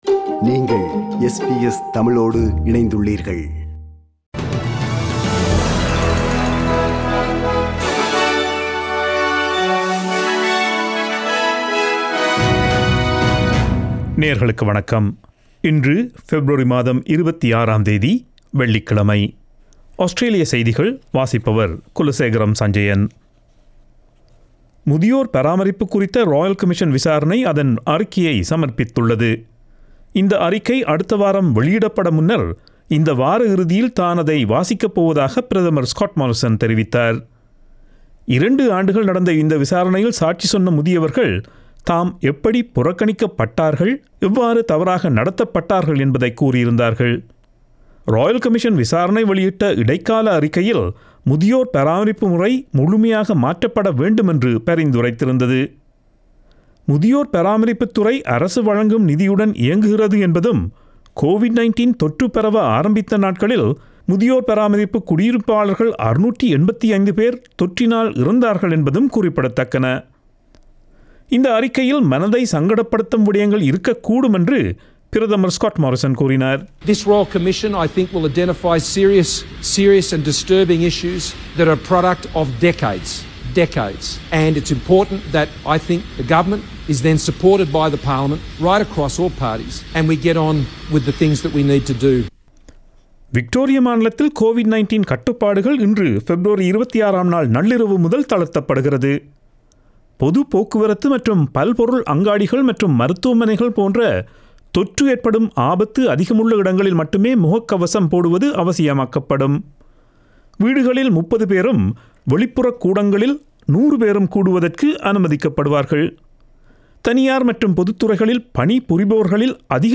Australian news bulletin for Friday 26 February 2021